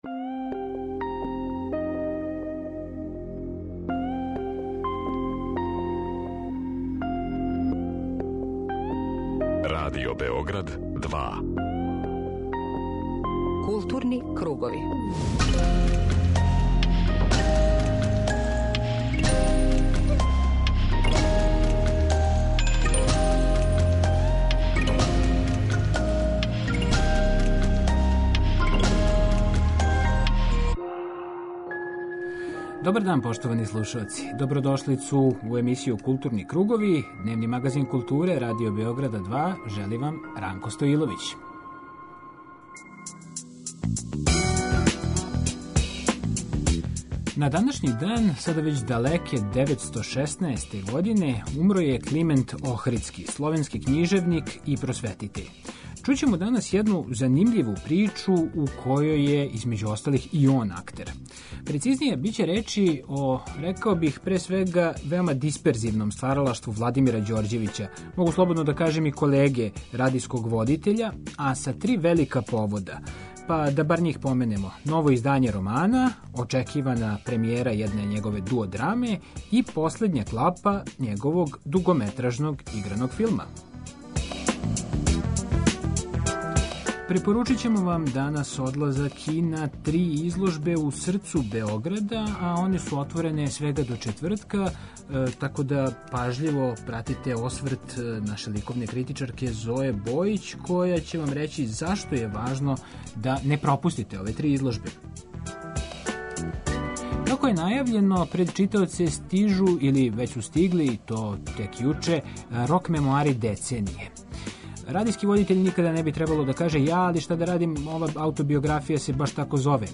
преузми : 19.70 MB Културни кругови Autor: Група аутора Централна културно-уметничка емисија Радио Београда 2.